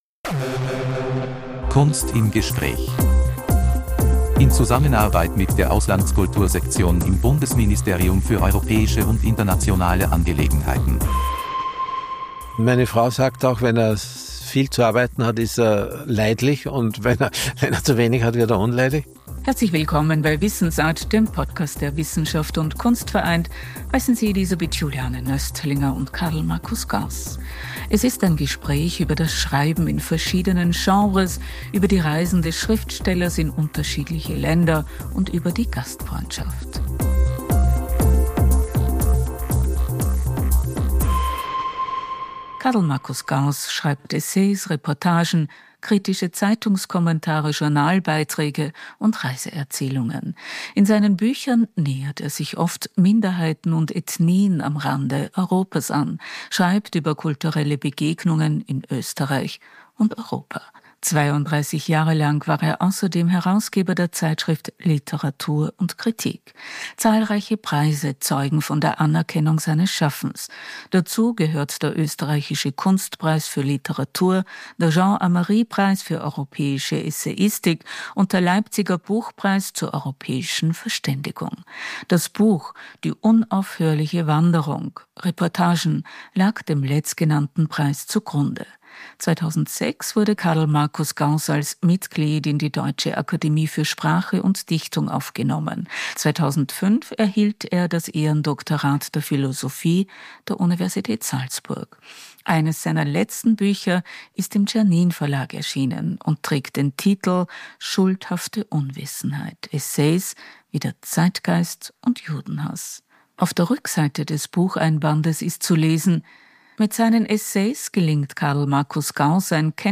Im „Kunst im Gespräch“ Podcast erzählt er bei wisssensART über den Alltag eines Schriftstellers und die Abenteuer des Reisens.